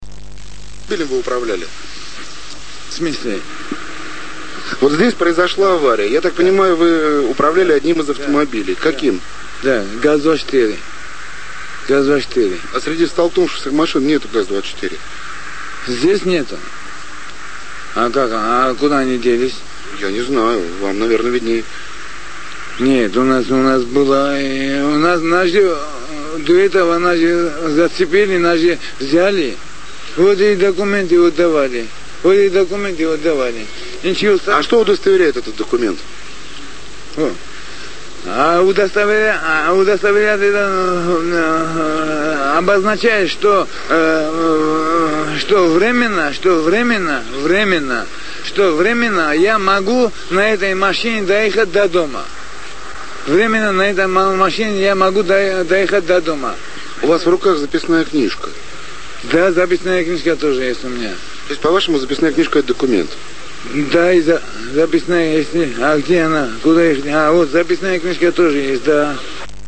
Хитовый репортаж :)